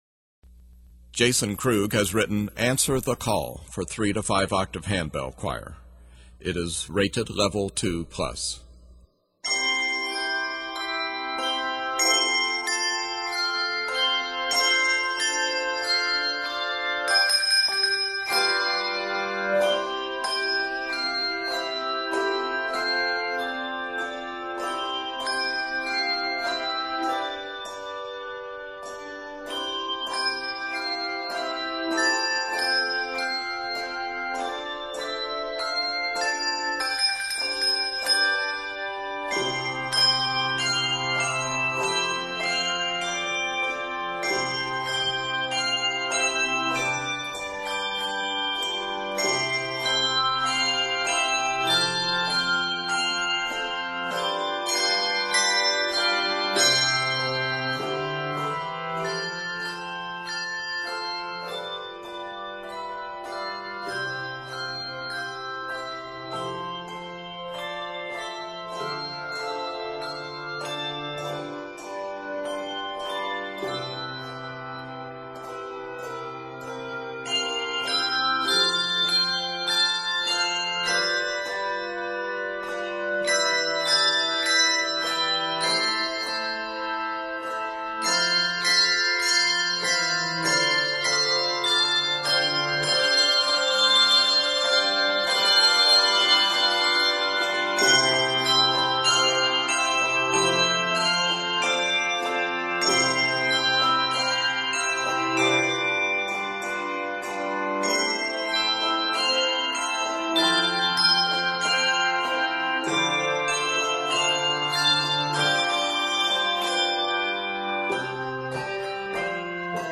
N/A Octaves: 3-5 Level